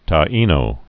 (tä-ēnō) also Tai·no (tīnō)